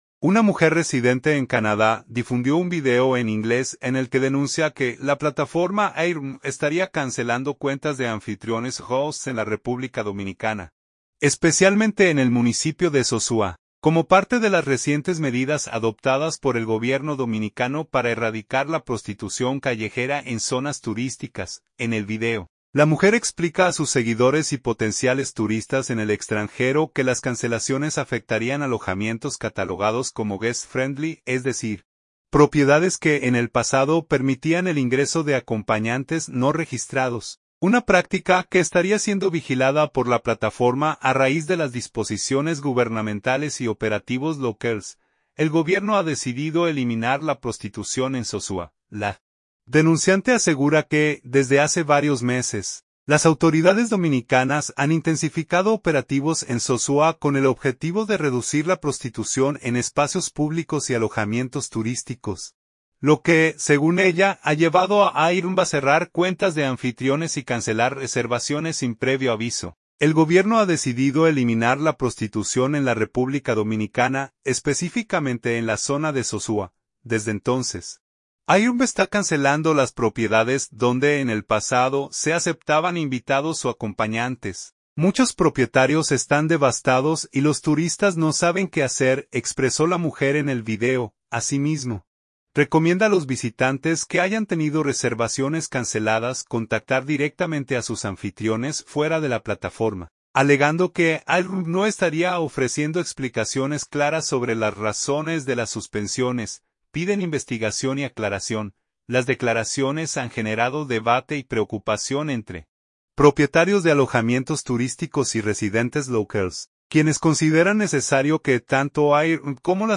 Sosúa, Puerto Plata – Una mujer residente en Canadá difundió un video en inglés en el que denuncia que la plataforma Airbnb estaría cancelando cuentas de anfitriones (hosts) en la República Dominicana, especialmente en el municipio de Sosúa, como parte de las recientes medidas adoptadas por el gobierno dominicano para erradicar la prostitución callejera en zonas turísticas.